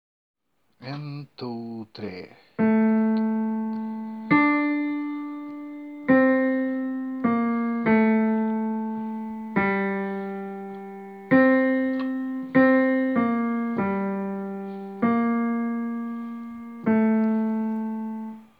Tenor: (takt 66)
tenor-julekvad.m4a